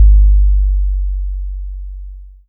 Track 14 - Kick OS 02.wav